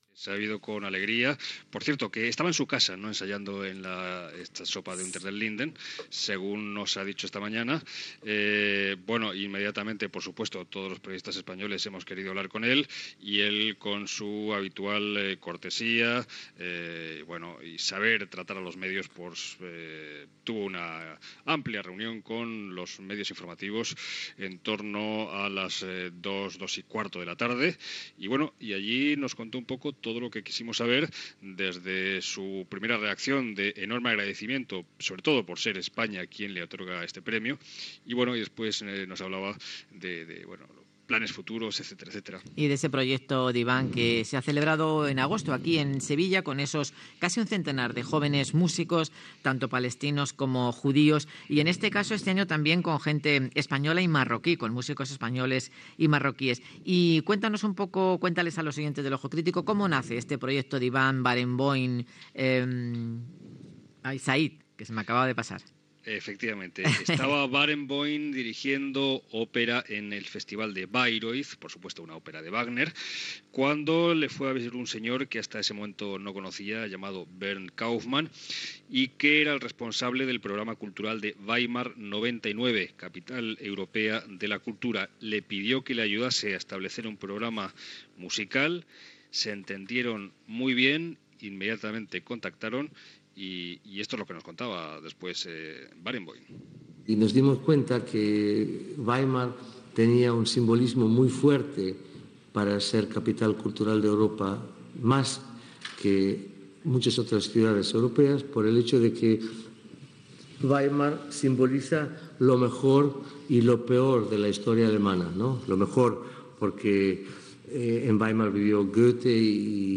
Informació, des de Berlín, sobre la concessió del premi Príncipe de Asturias de la Concordia a Daniel Barenboim i Edwars Said per la creació de l'Orquesta West-Eastern Divan l'any 1999 amb joves de l'Orient Mitjà